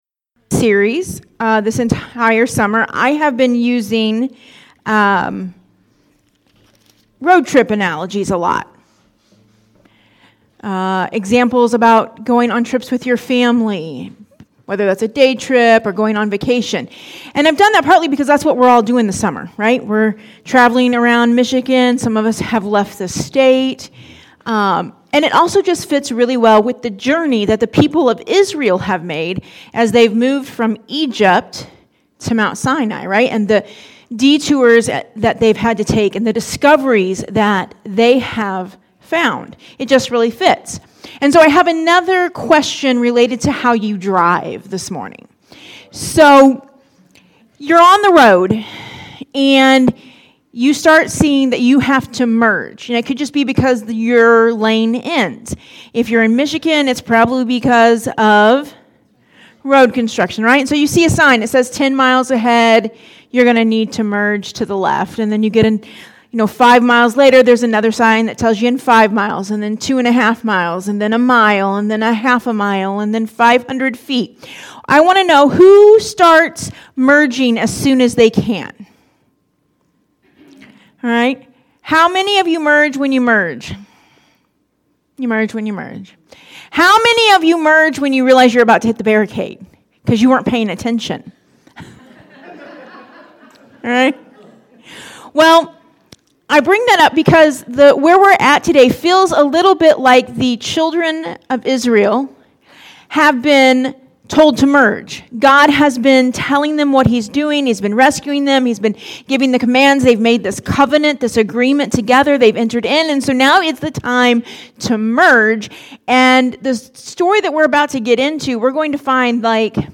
Sermons | Compassion Church